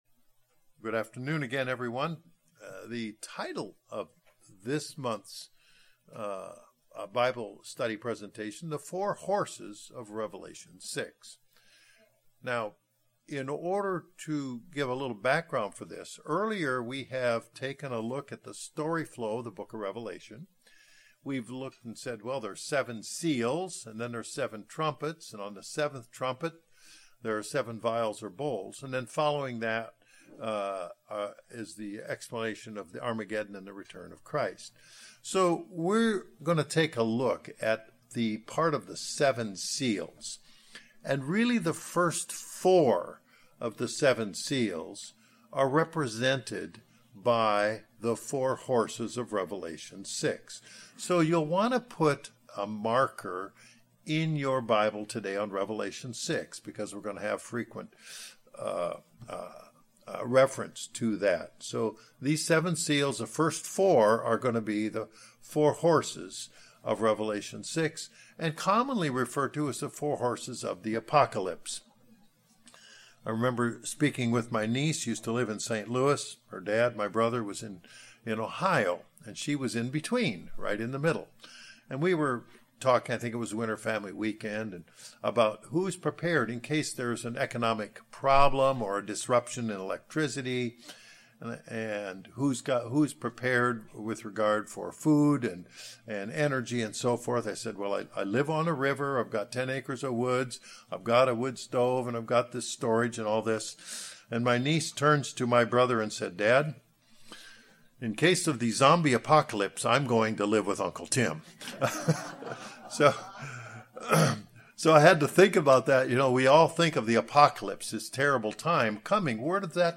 In this Bible Study the four horses of Revelation are reviewed and compared to the many events in history that reflect their descriptions.